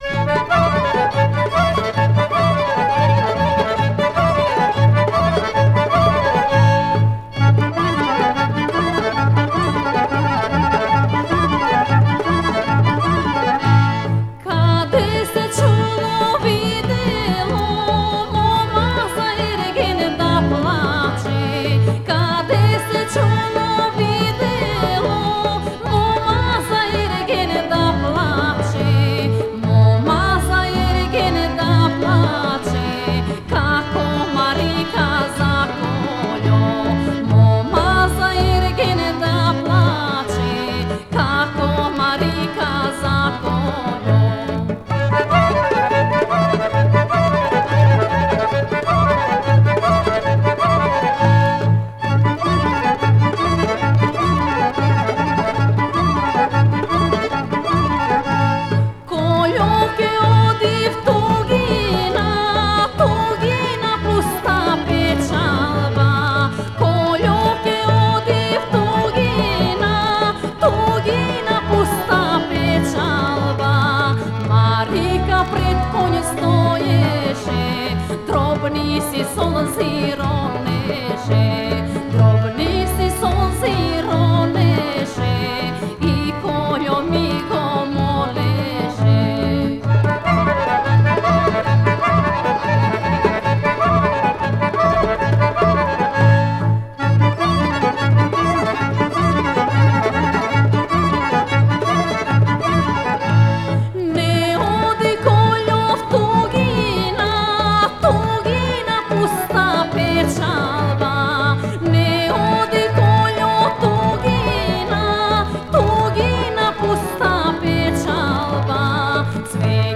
Genre: National Folk